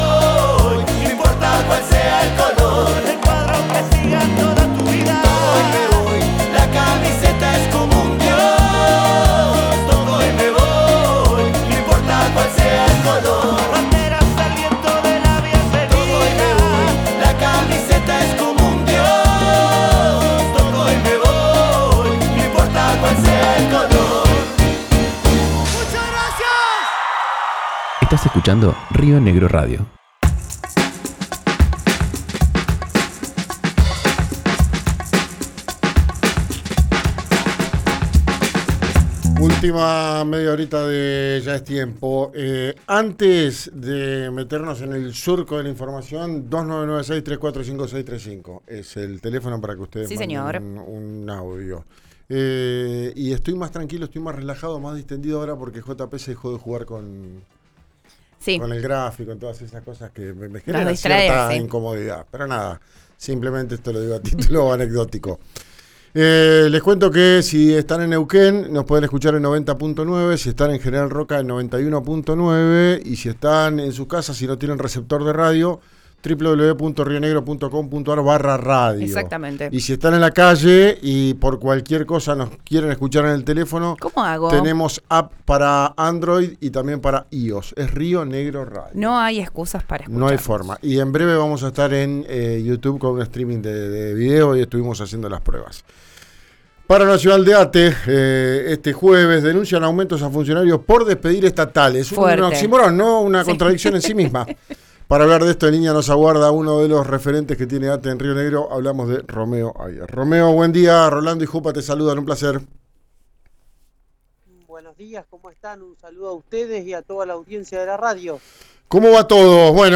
en diálogo con RÍO NEGRO RADIO. ¿Cómo afecta la medida a Neuquén y Río Negro?